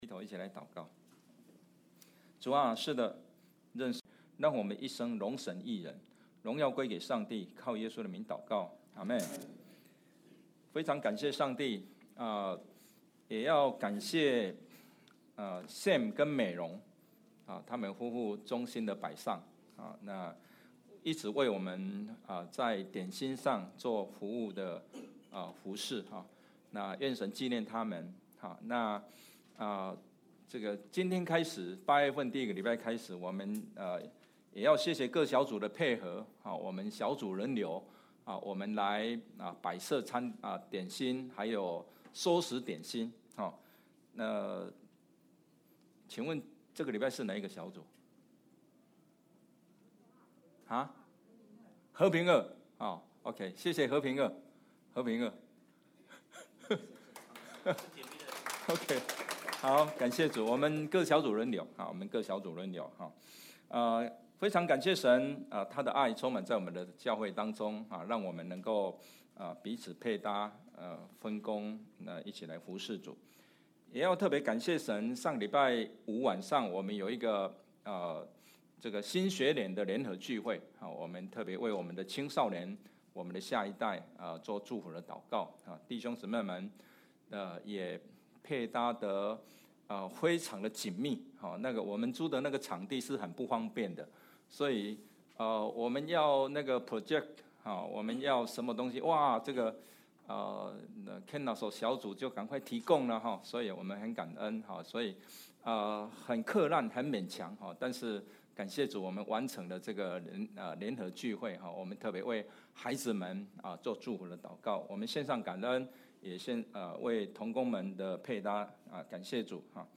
Passage: Psalm 42:5, 11 and 43:5; Luke 15:10–32 Service Type: 主日证道 Download Files Notes « 新歌之詩 耶穌佈道的三堂課 » Submit a Comment Cancel reply Your email address will not be published.